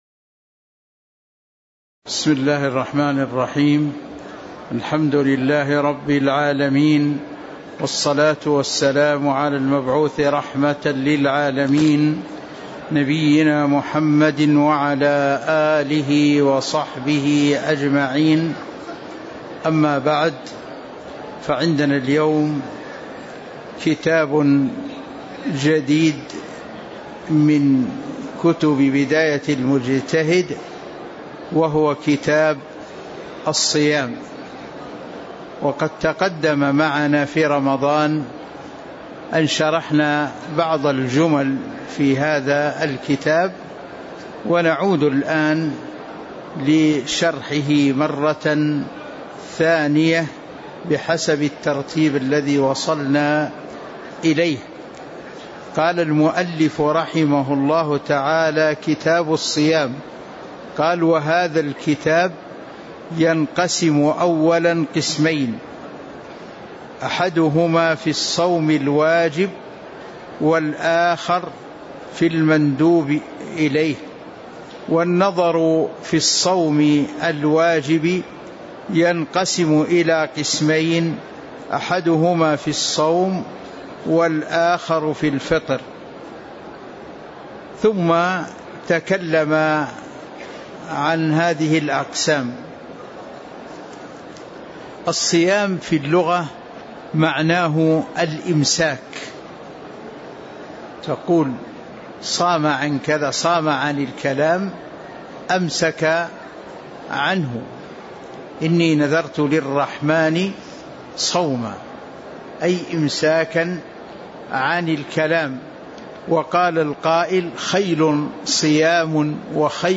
تاريخ النشر ٢٦ ربيع الأول ١٤٤٦ هـ المكان: المسجد النبوي الشيخ